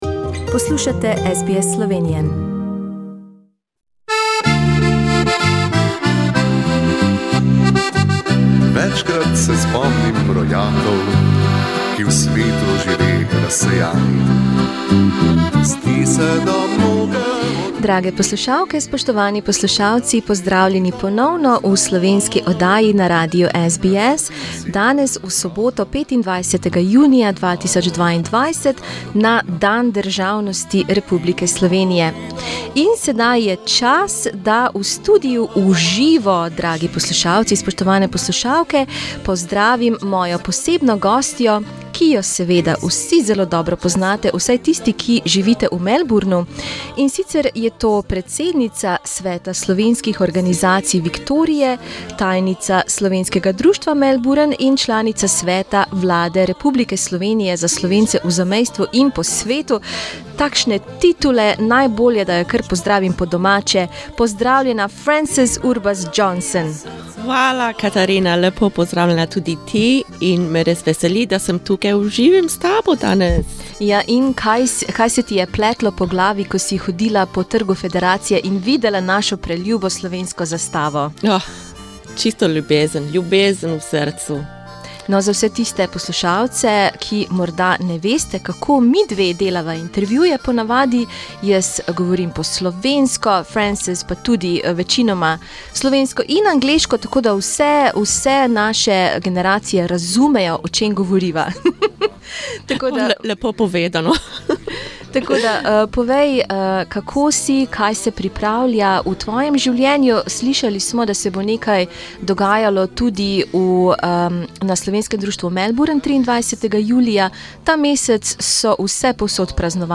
Posebna gostja v studiu
Prisluhnite prazničnemu klepetu.